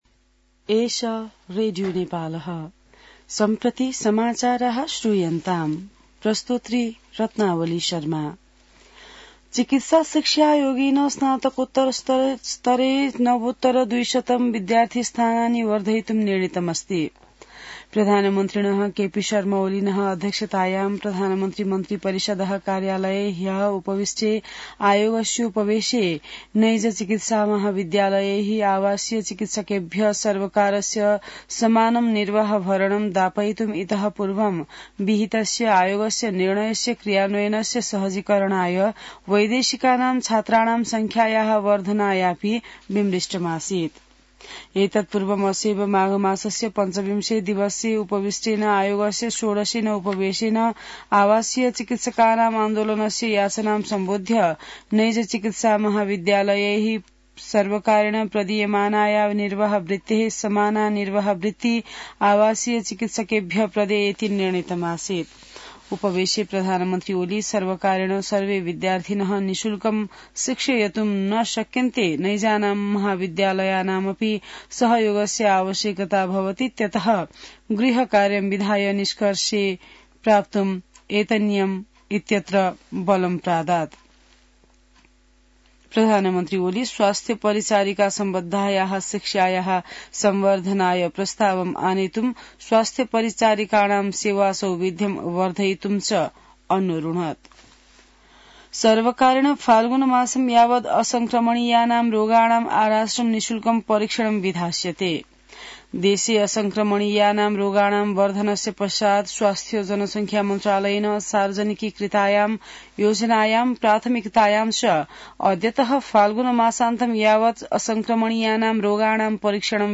संस्कृत समाचार : २ फागुन , २०८१